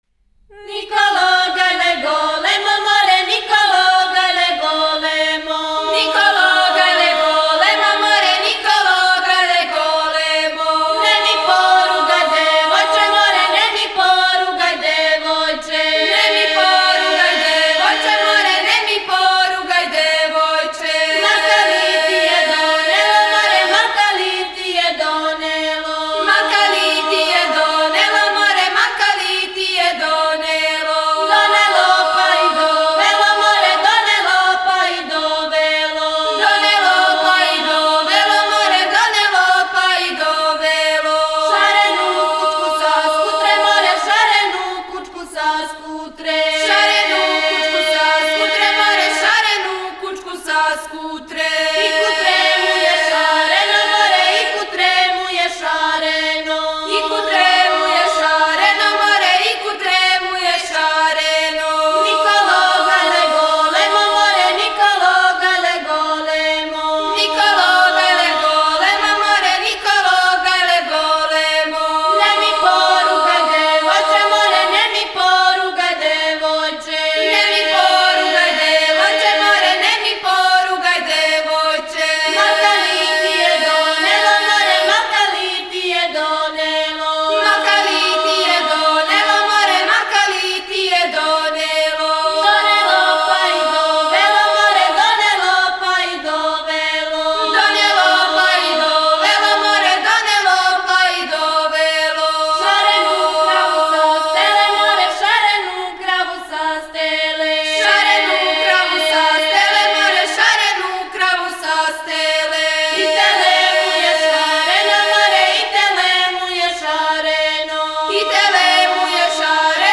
Етномузиколошки одсек Музичке школе "Мокрањац", Београд
Напомена: Песма уз игру.